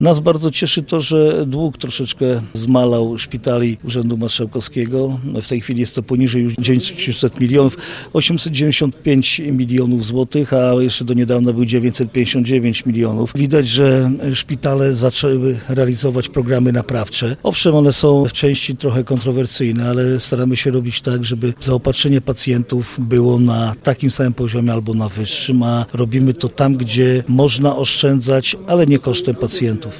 – Składają się z nowych-starych członków, więc tu nie ma żadnej rewolucji – mówi marszałek województwa lubelskiego Jarosław Stawiarski.